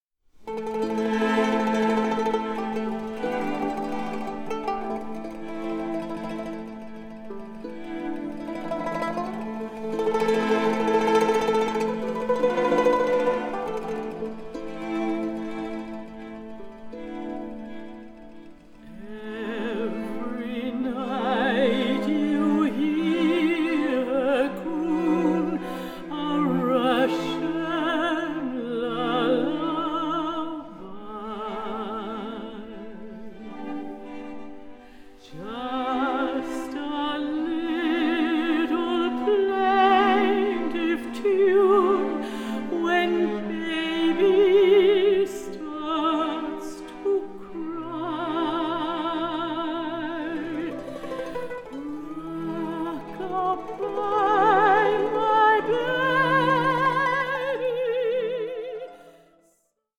HAUNTING, GENTLE SPIRITS, DREAMS, AND LULLABIES
all above a rich bed of cellos